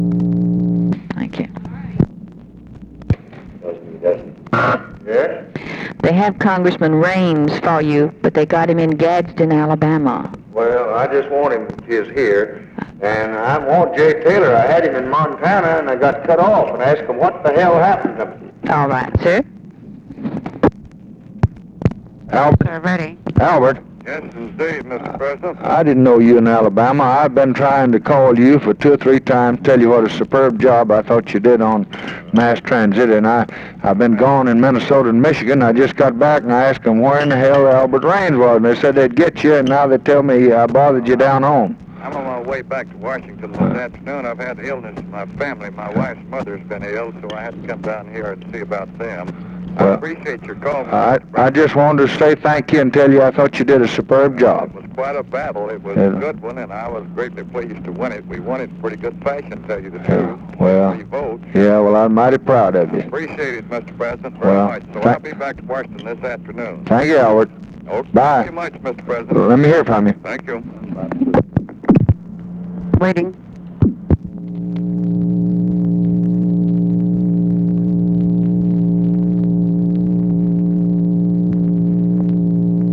Conversation with ALBERT RAINS and OFFICE SECRETARY, June 30, 1964
Secret White House Tapes